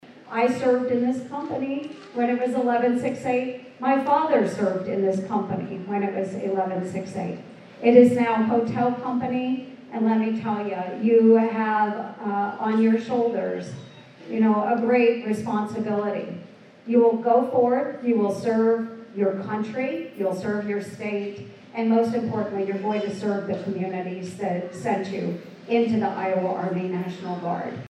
United States Senator Joni Ernst was on hand in her hometown to send Hotel Company out on its mission.  Ernst says it was almost 22 years ago when she was taking her own unit overseas to conduct Operation Iraqi Freedom.